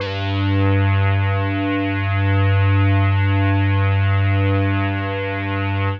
G2_trance_lead_2.wav